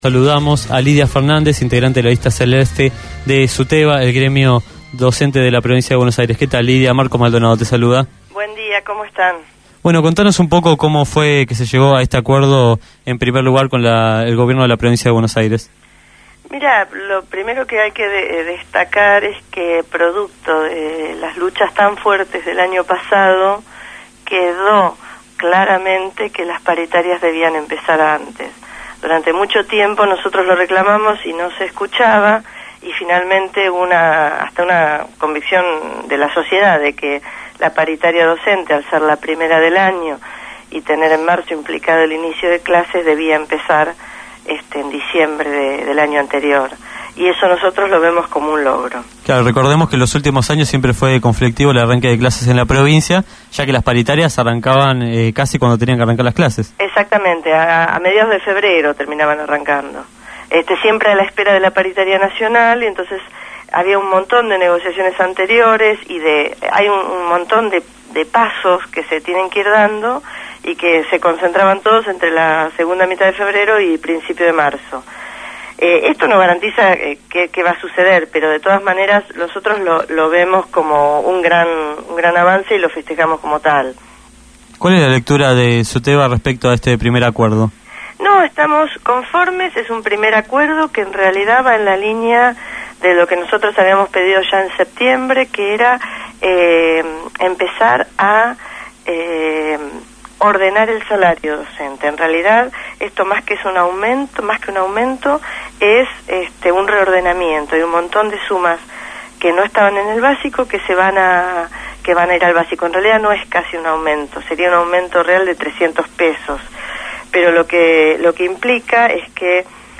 fue entrevistada en Punto de Partida. En el aire de Radio Gráfica, reveló la postura de su sindicato respecto a las paritarias docentes en Provincia de Buenos Aires.